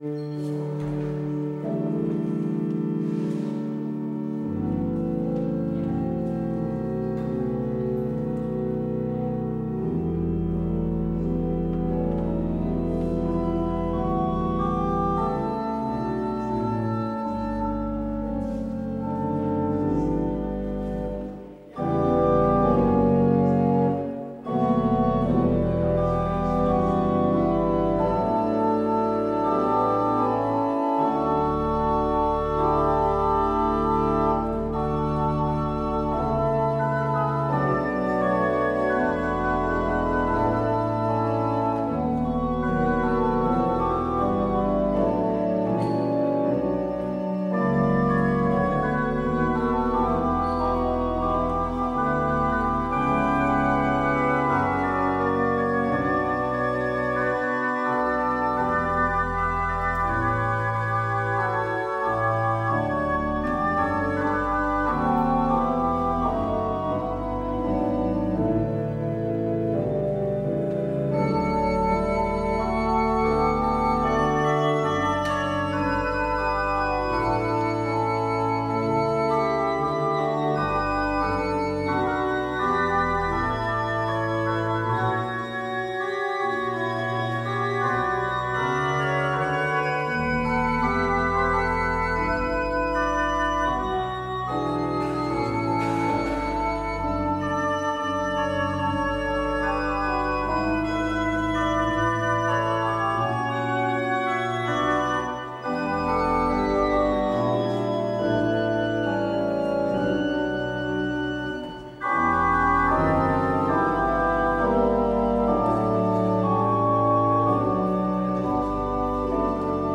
 Beluister deze kerkdienst hier: Alle-Dag-Kerk 2 juli 2025 Alle-Dag-Kerk https